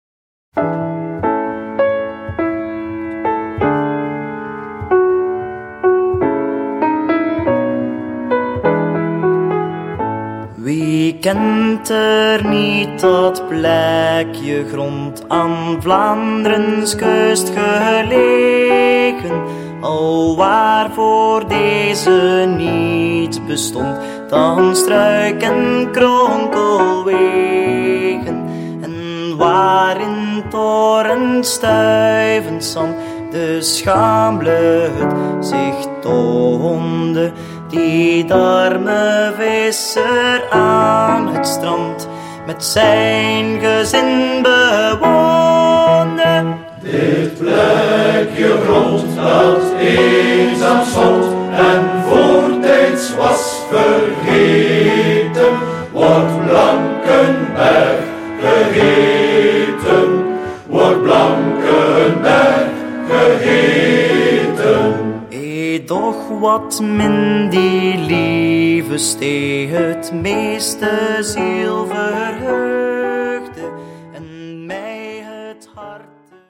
Opgenomen in 2016 - DK Studio Destelbergen